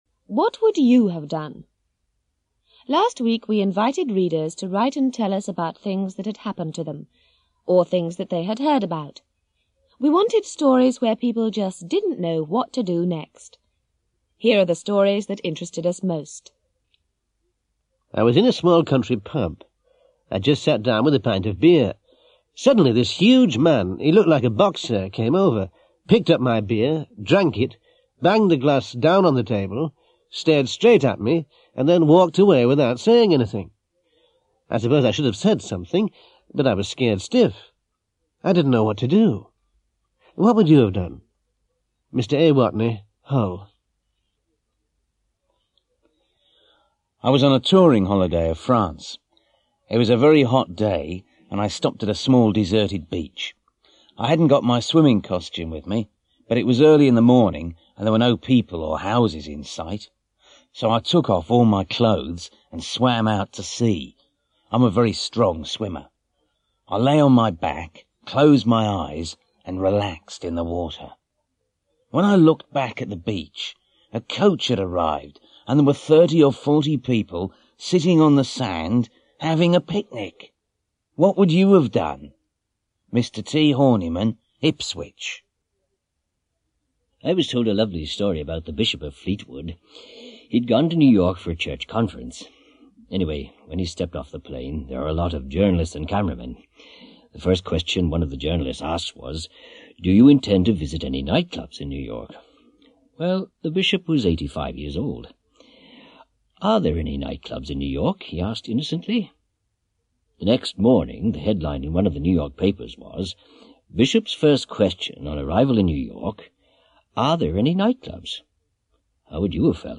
English conversation